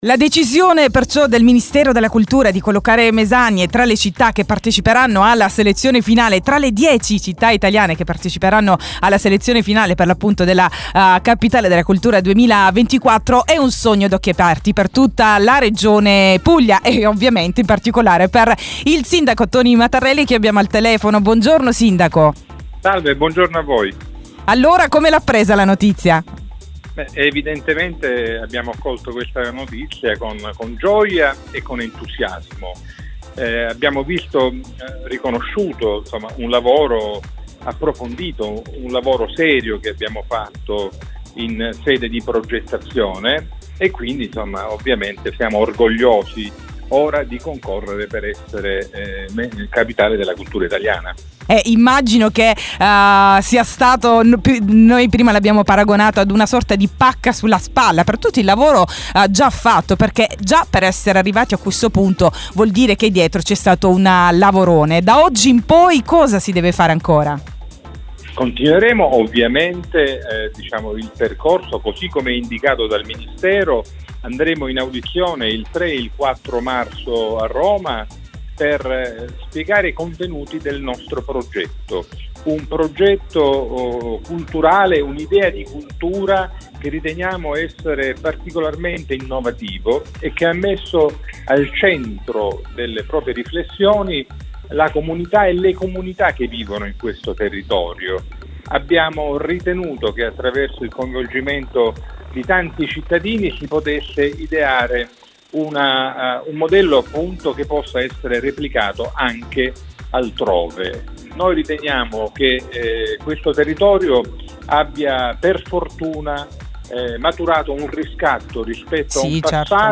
Mesagne tra le 10 città finaliste per il titolo di Capitale Italiana della cultura 2024. La gioia e la soddisfazione del sindaco Toni Matarrelli in diretta ne "Il mattino di Radio 85".